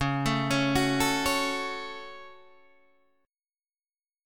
C# Suspended 4th Sharp 5th